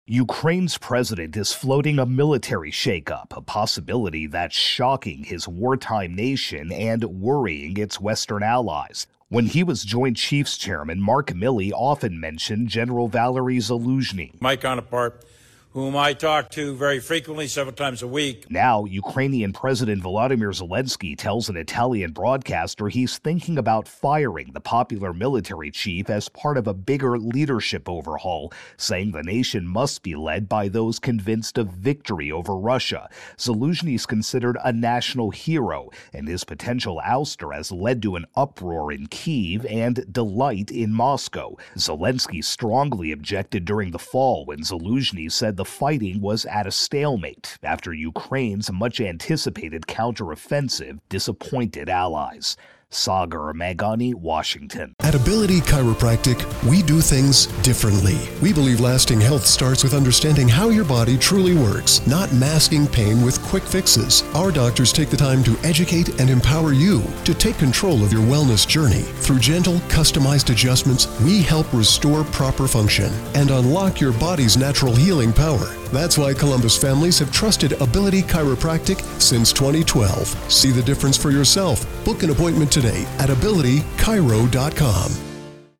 AP Washington correspondent